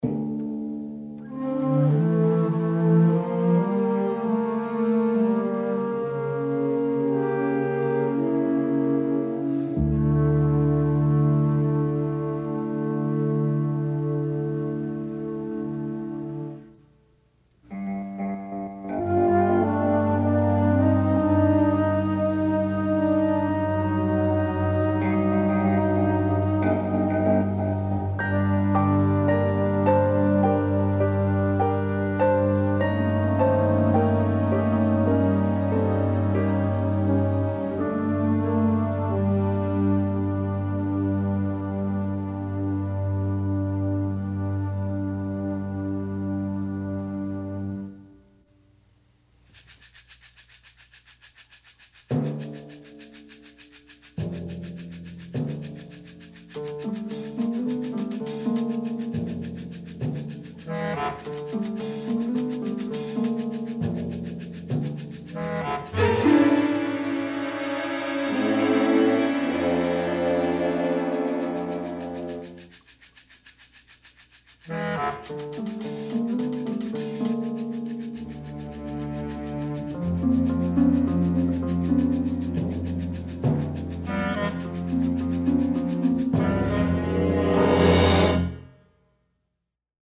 swinging orchestral soundscape